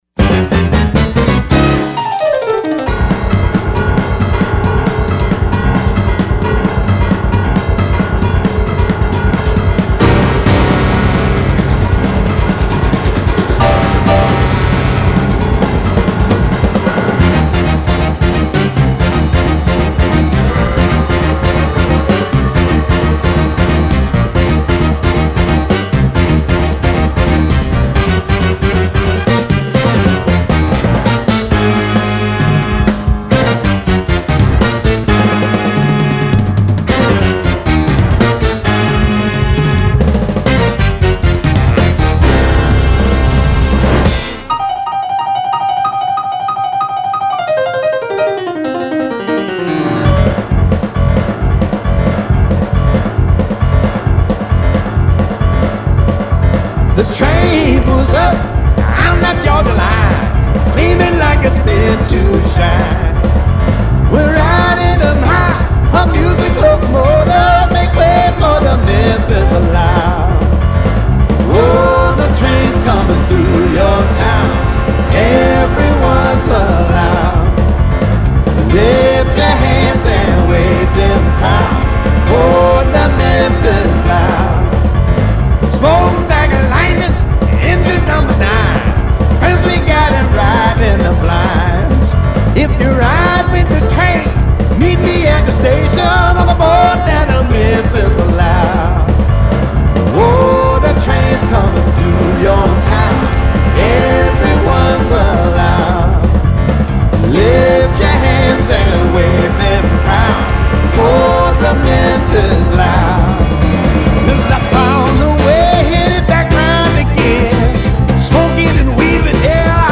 De fait cet album apparaît plus varié, plus doux.